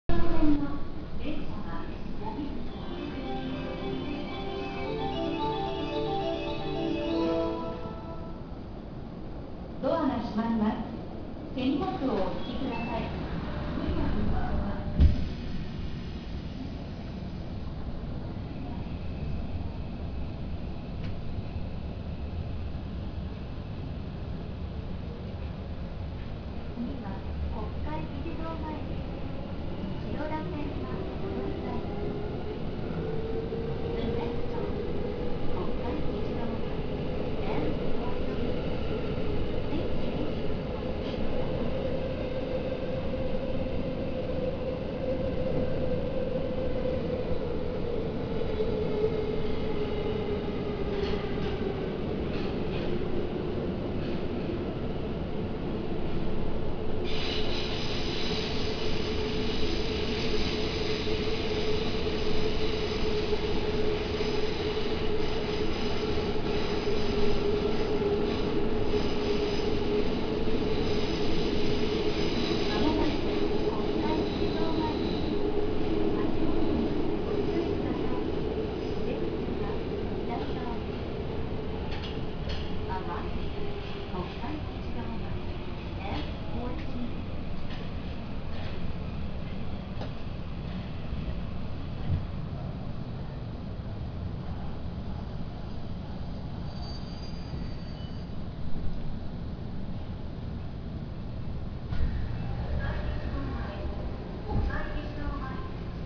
〜車両の音〜
・2000系東芝SiC+PMSM走行音
【丸ノ内線】霞ケ関→国会議事堂前（3分7秒：577KB）
特徴のある音ではありますが、02系のPMSMと比べると音量は控えめなように思えます。